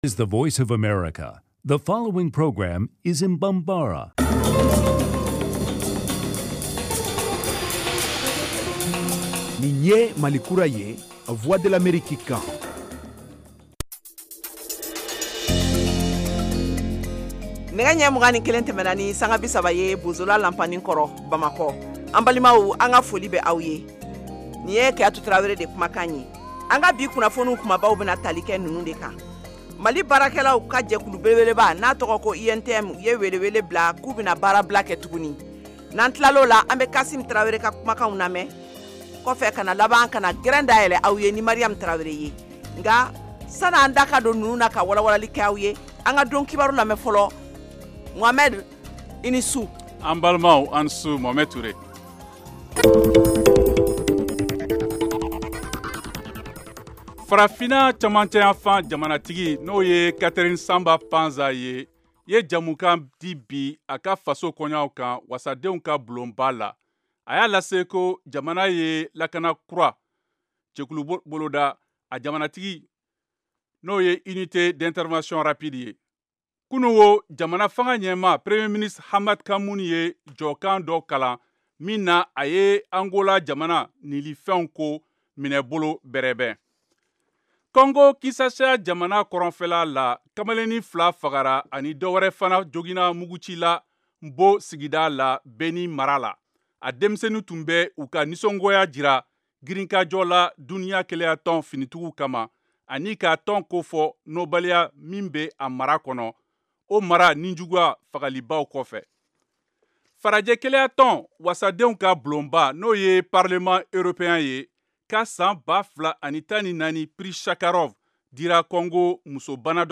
en direct de Washington